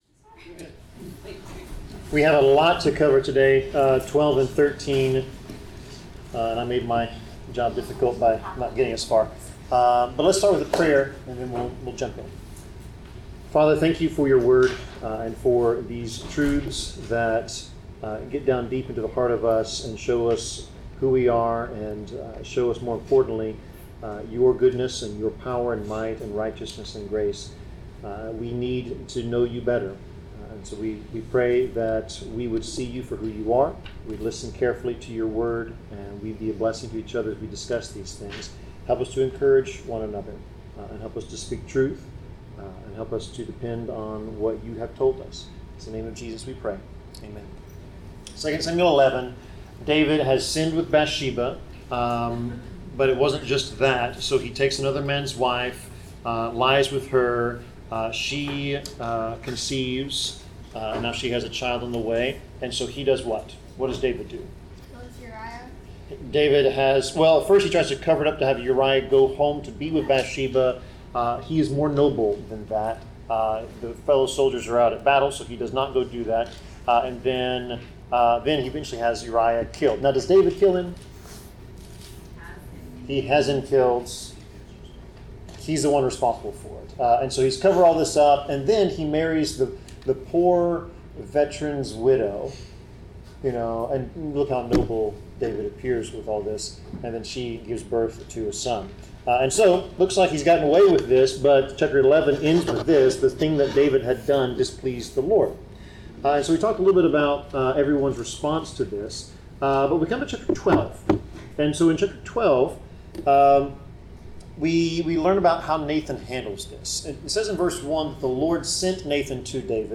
Bible class: 2 Samuel 12-13 (Sin in the House of David)
Service Type: Bible Class